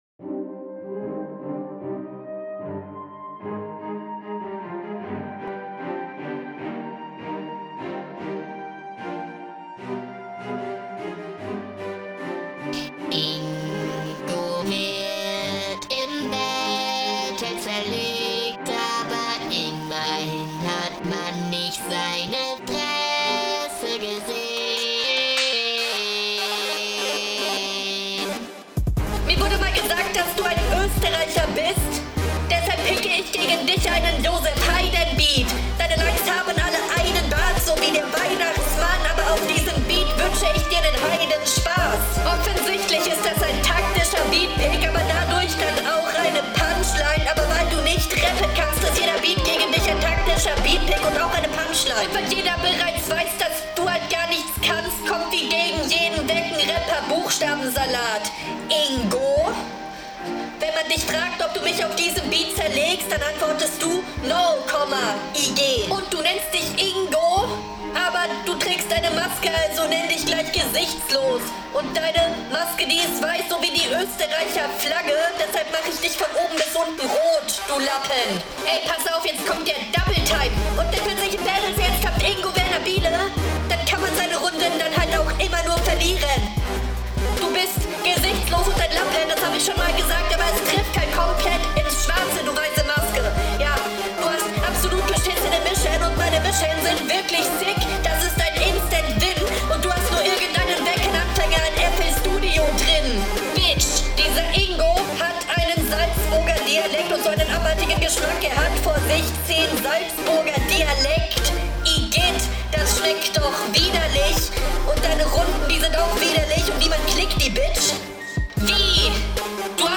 Ja ok freestyle type freestyle auf eklig type eklig äh beat Pfosten und Salzburger Line …